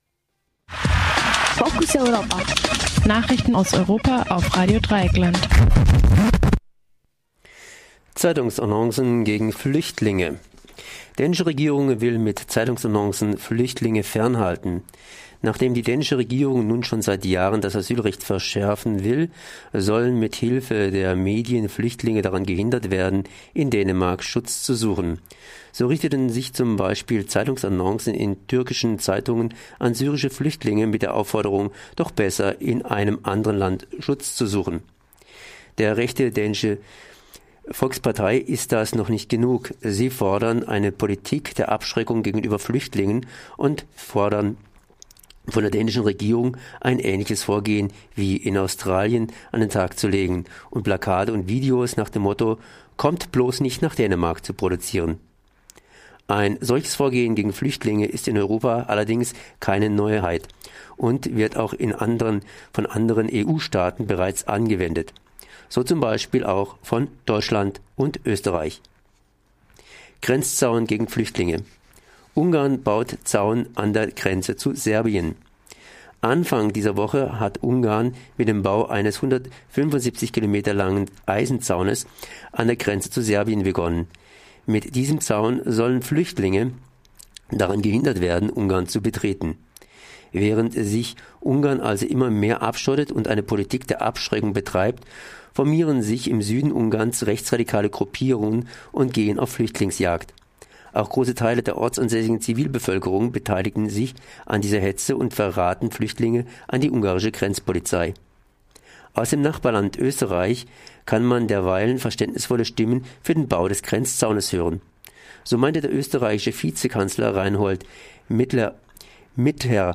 Focus-Europa Nachrichten, 05. August 2015